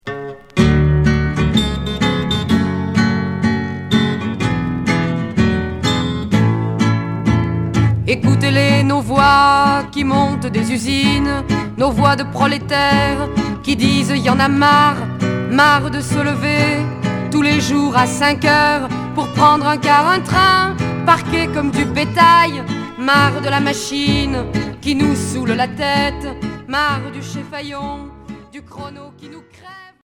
Chanson folk révolutionnaire